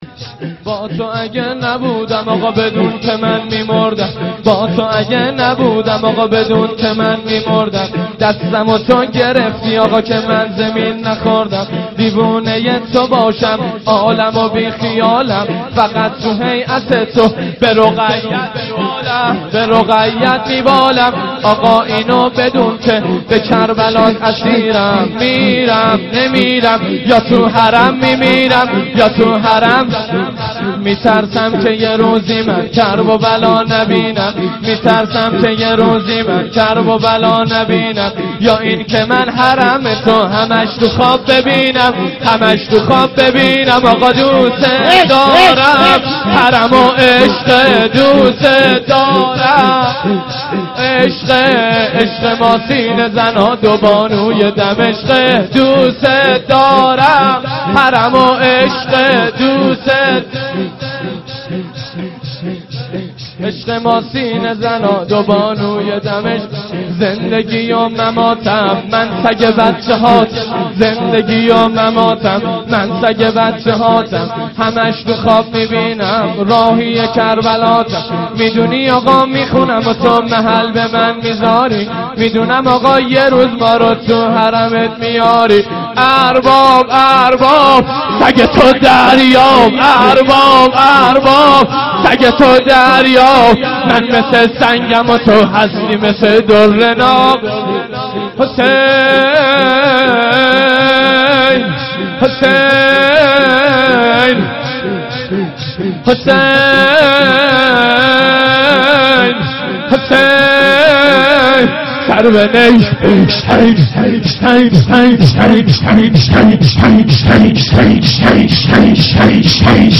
شاید بسیاری‌ از مداحی‌های امروز، ملودی‌های خود را از آثار مشهور موسیقی پاپ وام گرفته‌اند و این شیوه دیگر تبدیل یک روال عادی شده است اما تهران قدیم، سال‌های سال مداحانی داشته که هیچ‌گاه به خود اجازه ندادند با زبان محاوره سیدالشهدا(ع) و یارانش را خطاب کنند.